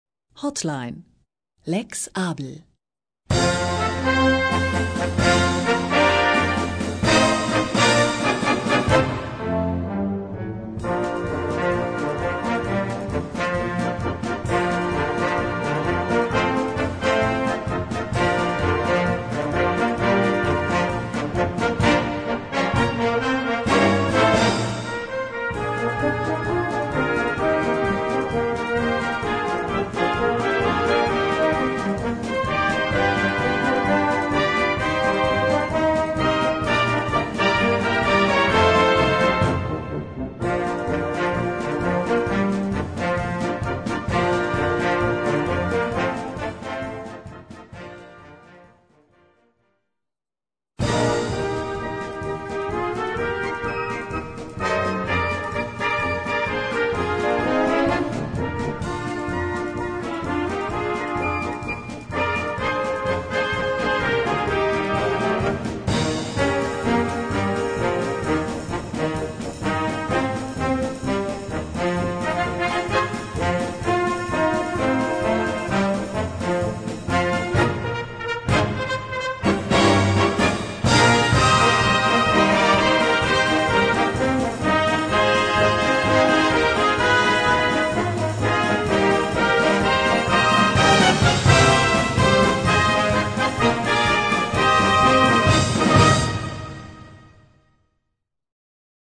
Gattung: Calliope
Besetzung: Blasorchester